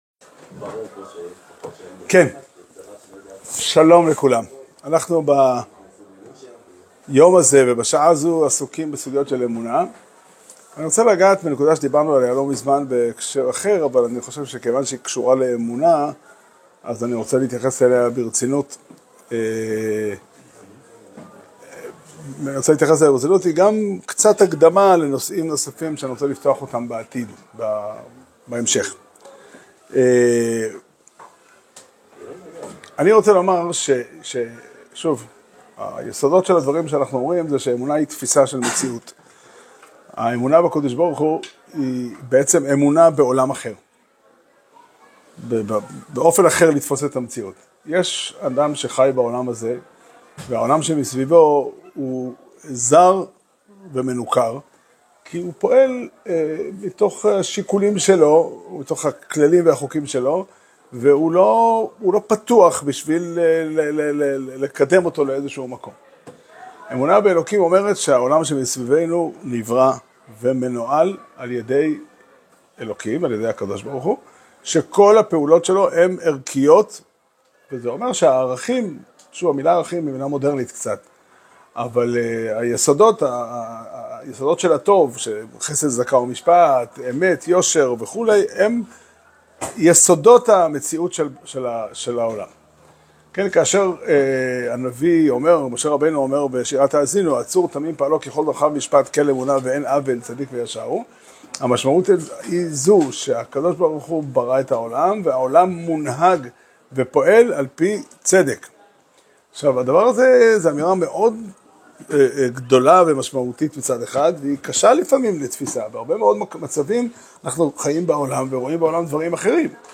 שיעור שנמסר בבית המדרש פתחי עולם בתאריך י"ט חשוון תשפ"ה